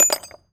metal_small_movement_03.wav